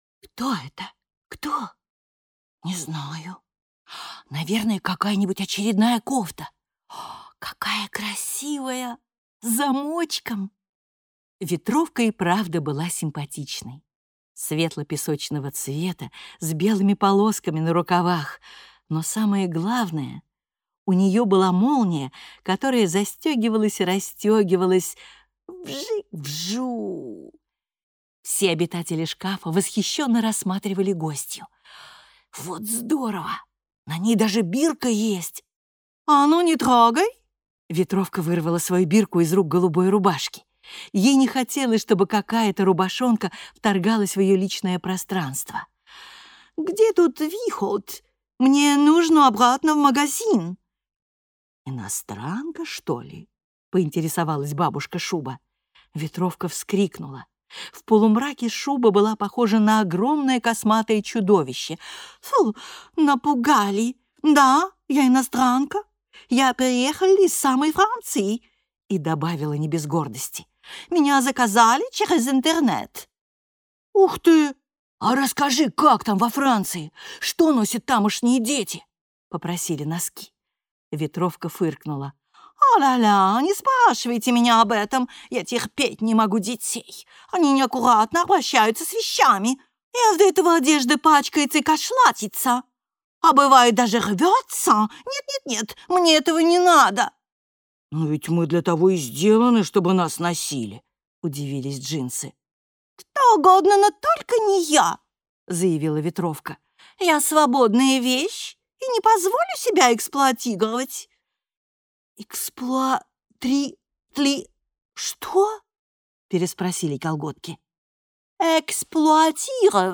Аудиокнига Однажды в шкафу | Библиотека аудиокниг